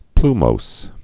(plmōs)